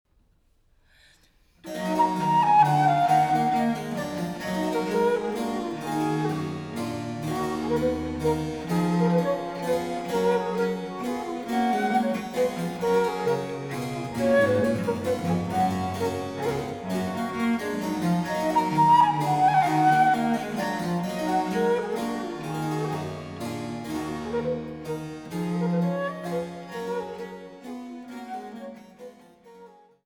Traversflöte
Cembalo